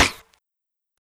True Colours Snare.wav